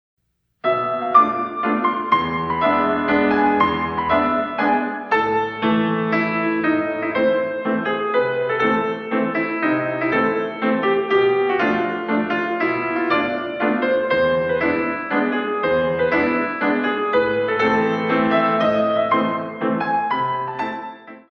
In 3
128 Counts